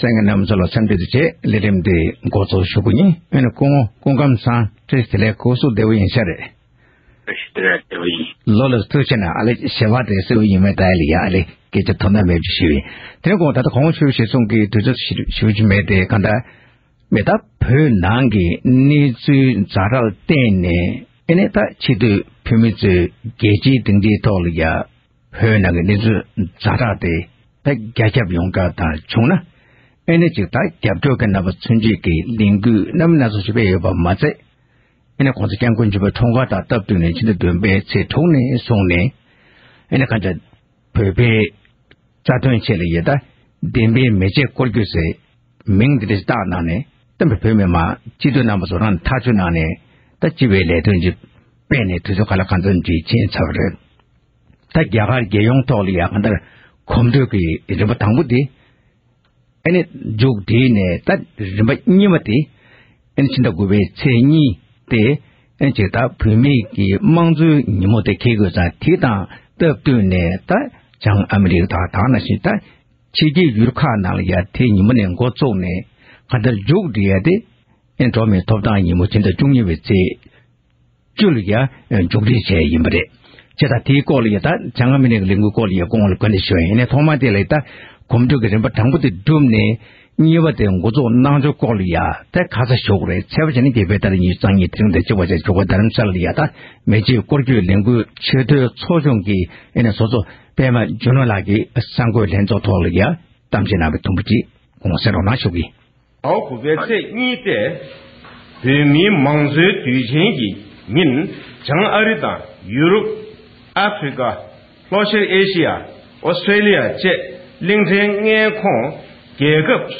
གླེང་མོལ་གནང་བར་གསན་རོགས༎